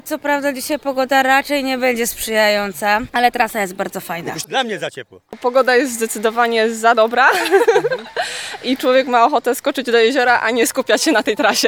– mówili sportowcy tuż przed biegiem